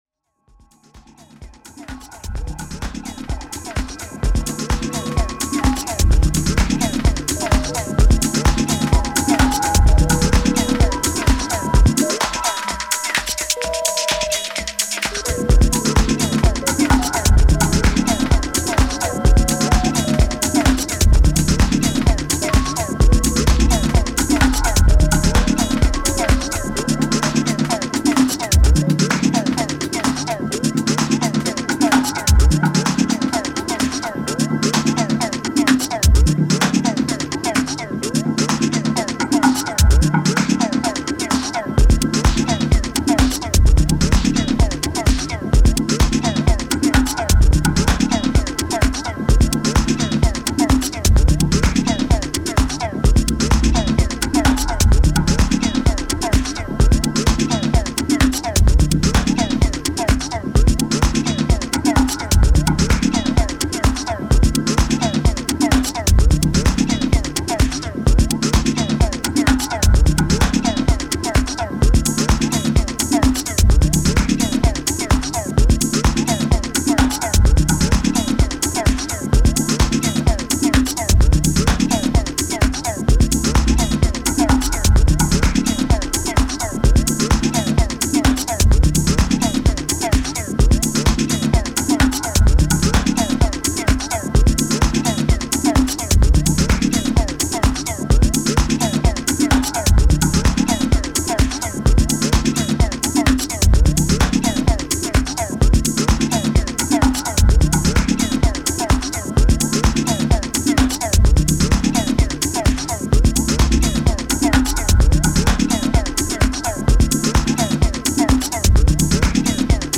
FILE UNDER: Minimal House, Minimal Techno, House, Techno